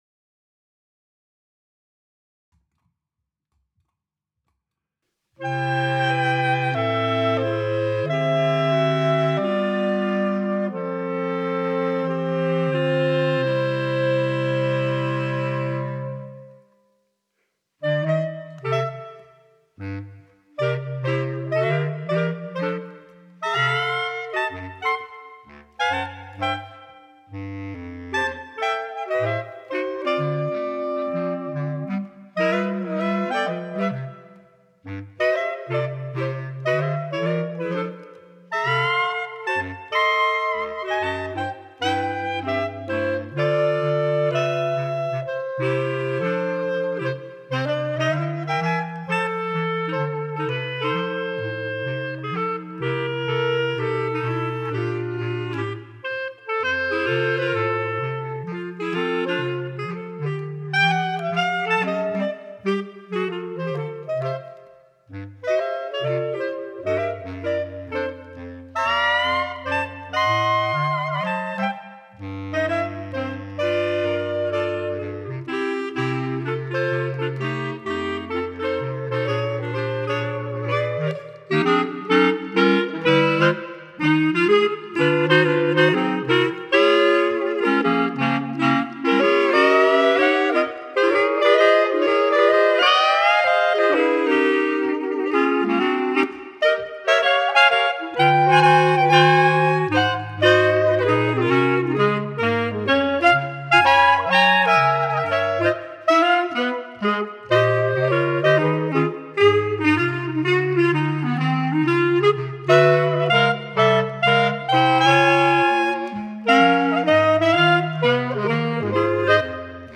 Bass Clarinet Lowest Note: E1